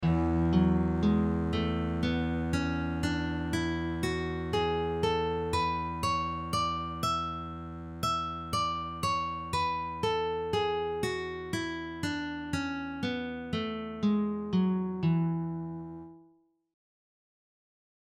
- > E Mixolydian
E+Mixolydian.mp3